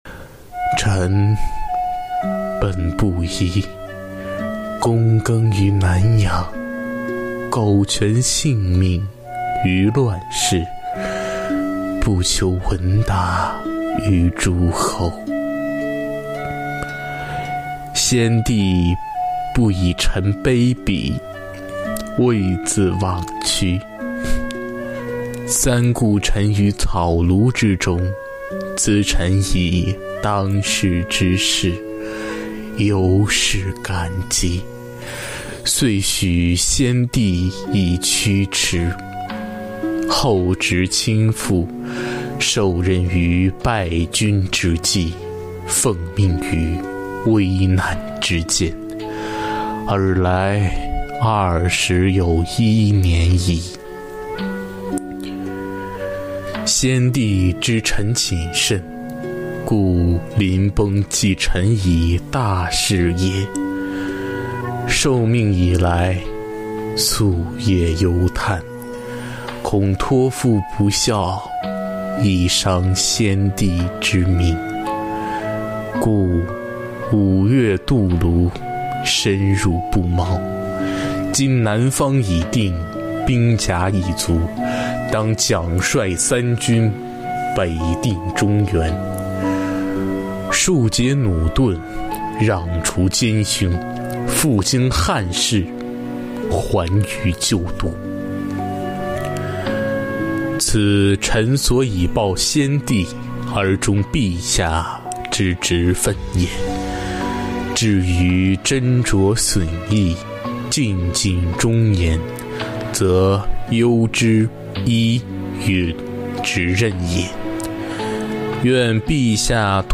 诵中华经典，品古韵流芳----中华经典诵读大赛圆满落幕